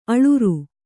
♪ aḷuru